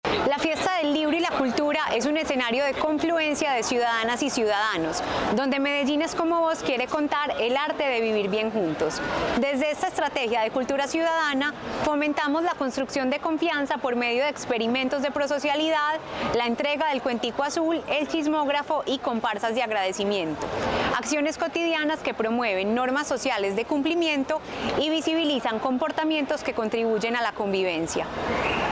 Palabras de Natalia Londoño, subsecretaria de Ciudadanía Cultural